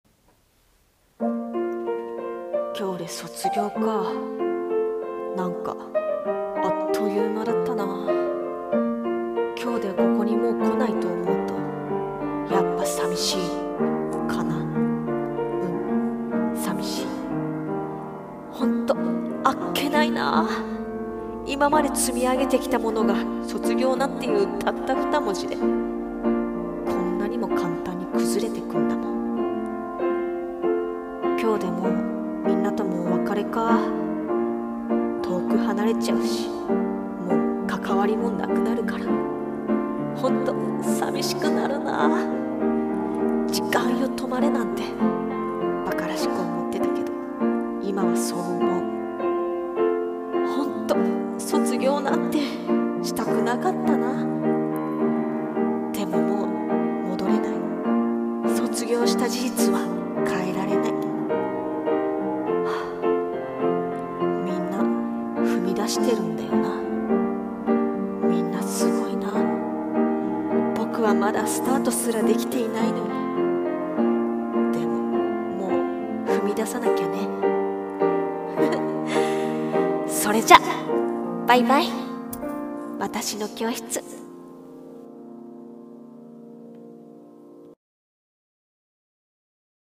[声劇台本]